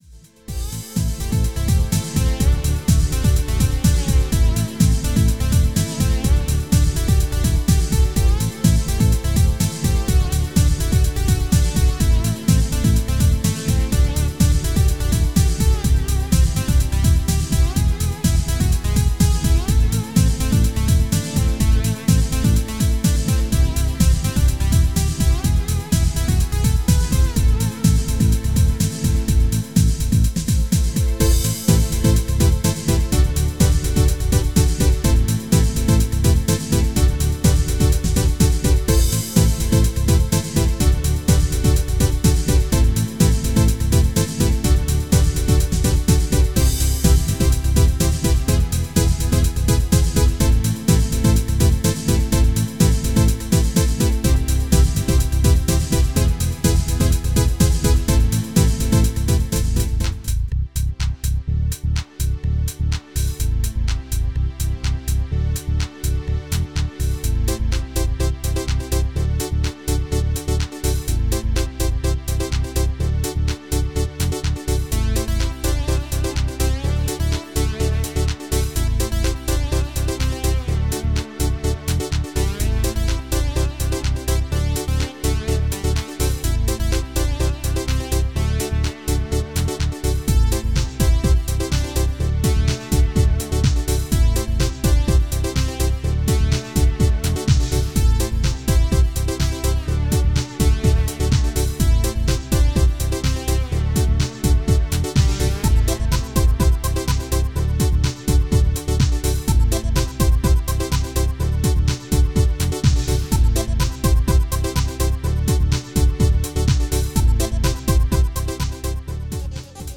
Music bundle containing 4 tracks in a synth 90s style.